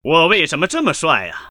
男人说我为什么这么帅音效_人物音效音效配乐_免费素材下载_提案神器